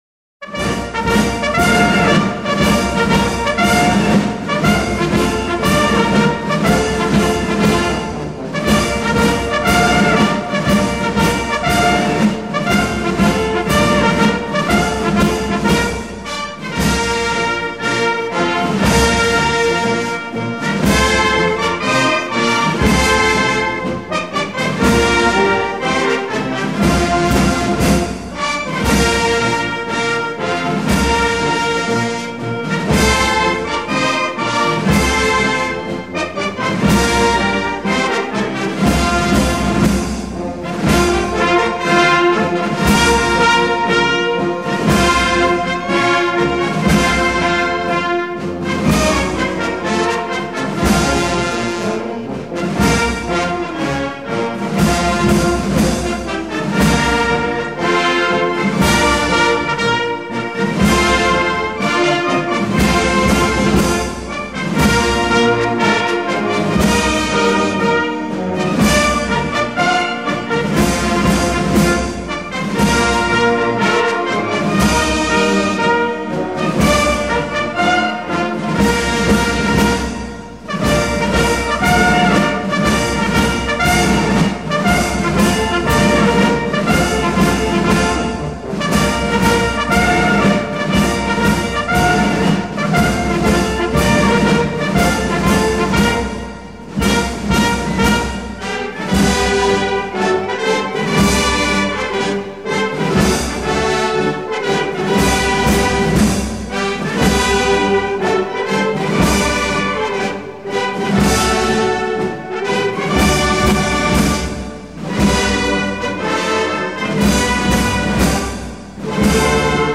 SONNERIES MILITAIRES ADAPTÉES AUX CÉRÉMONIES
Marches militaires [ 8 mai ]